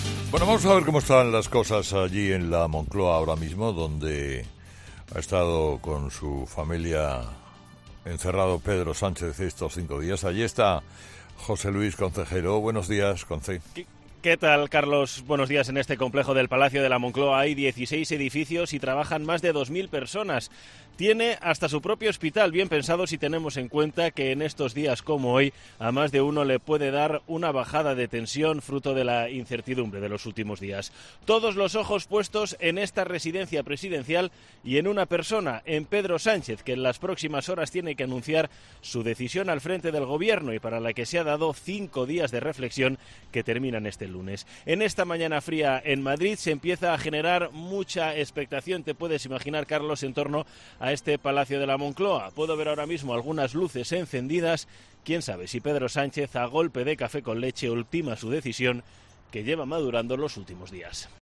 En COPE te contamos, desde primera hora de la mañana, cuál es la situación en Moncloa, a la espera del gran anuncio.